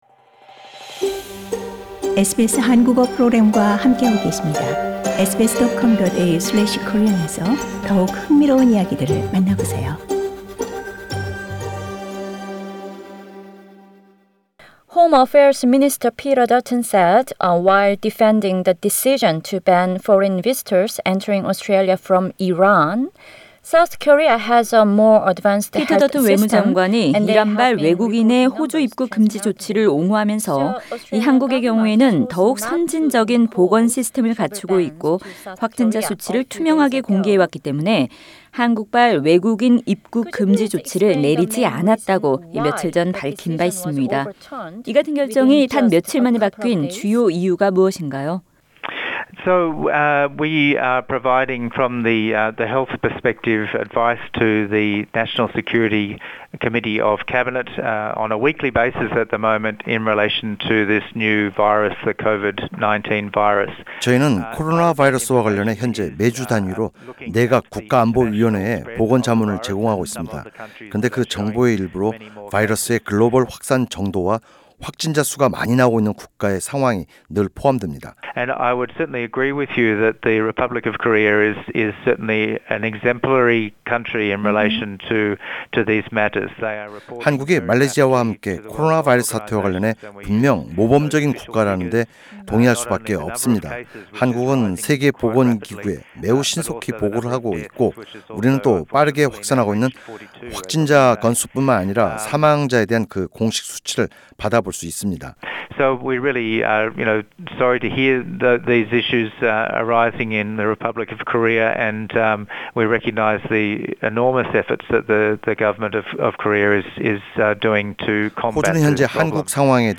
[특별대담] 연방차석의료최고 책임자 폴 켈리 교수 "한국발 입국제한은 불가피"